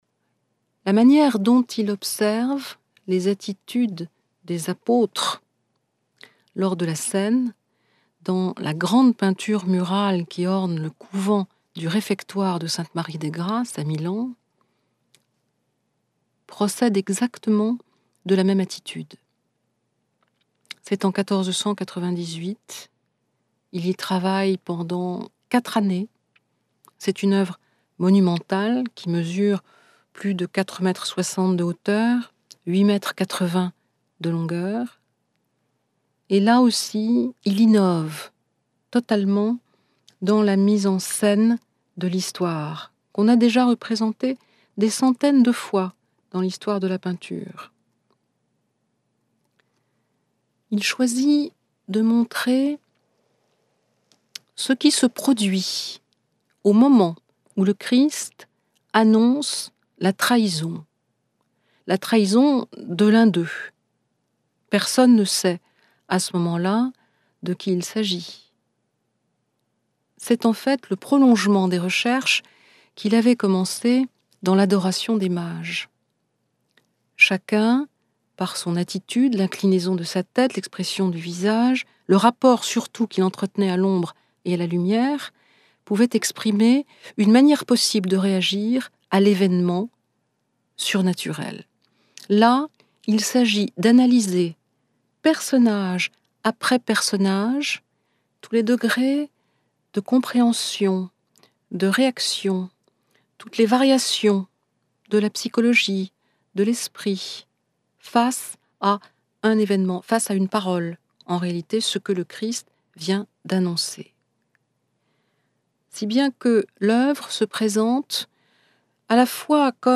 enregistré sur le vif de la parole